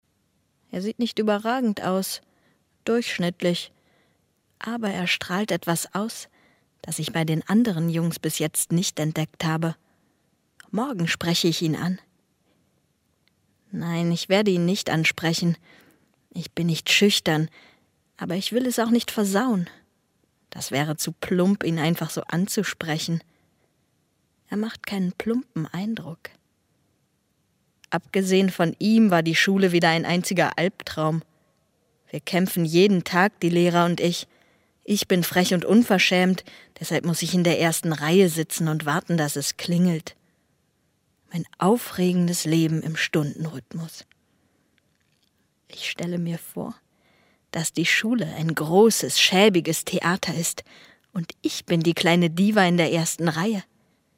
Profi - Sprecherin mit junger, variabler Stimme von naiv bis kompetent
Kein Dialekt
Sprechprobe: Industrie (Muttersprache):